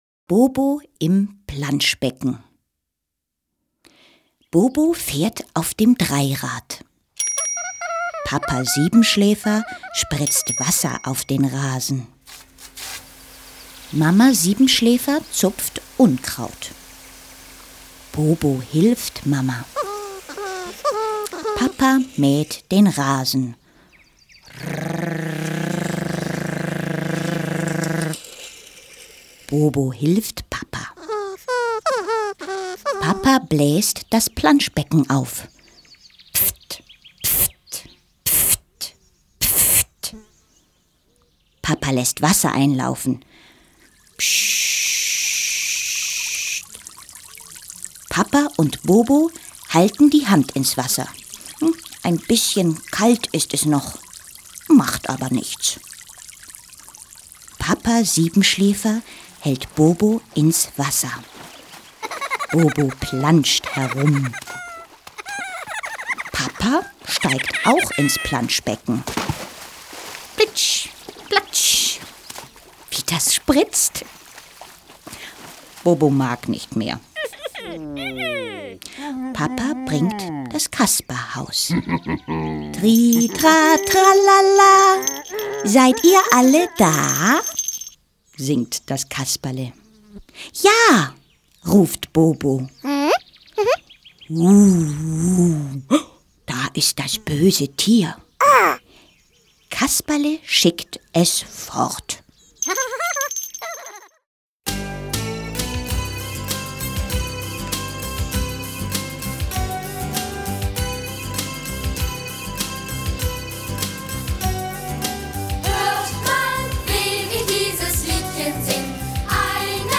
Geschichten für ganz Kleine mit KlangErlebnissen und Liedern